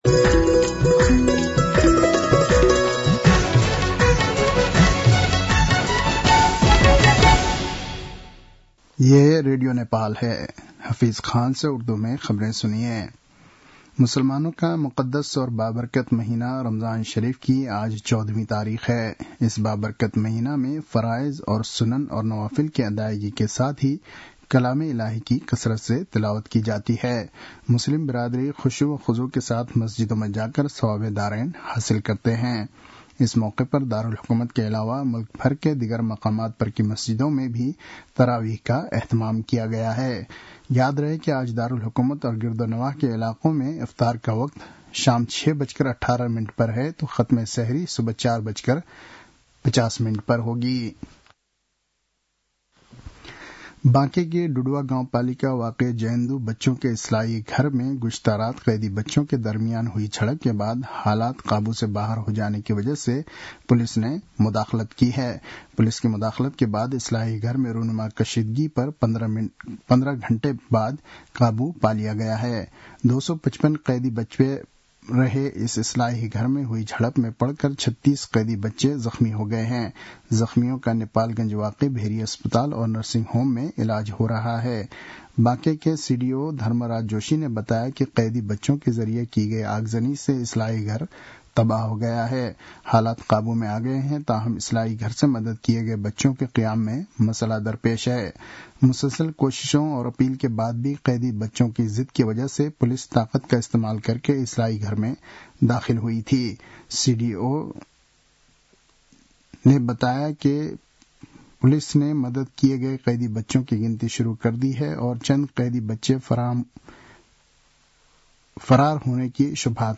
An online outlet of Nepal's national radio broadcaster
उर्दु भाषामा समाचार : २ चैत , २०८१